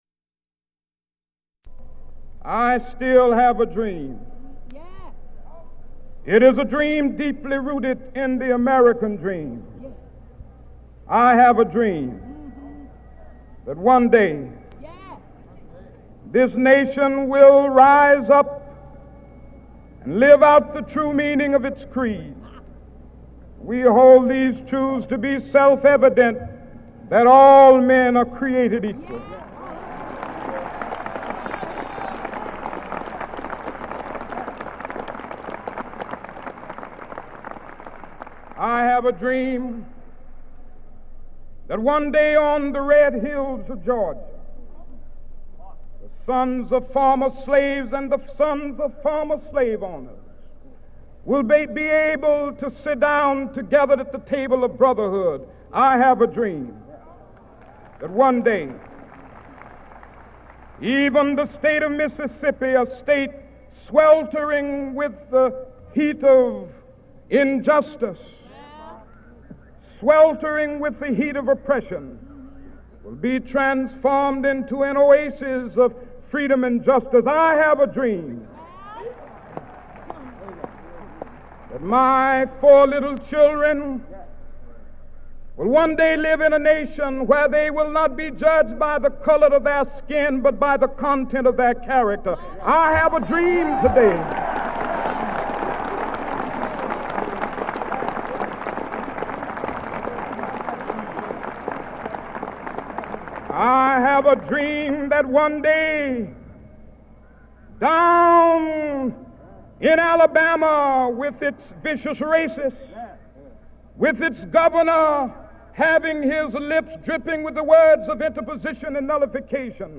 Audiocassette